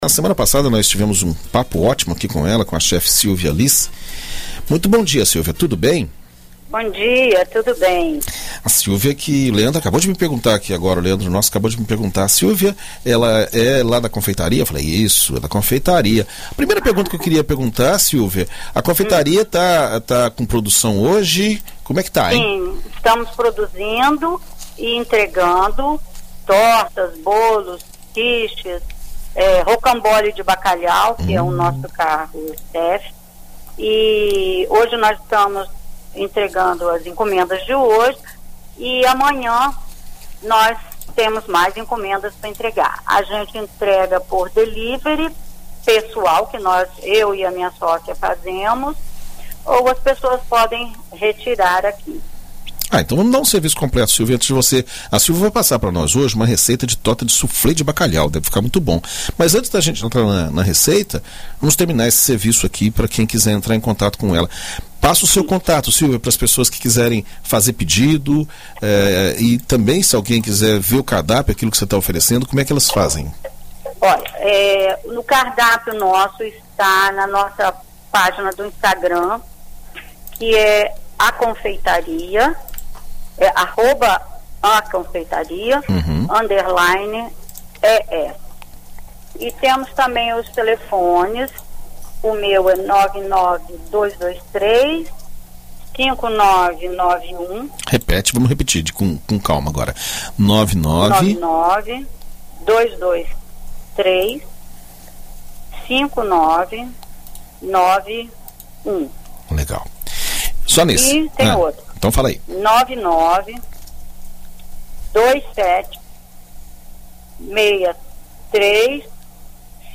Em entrevista à BandNews FM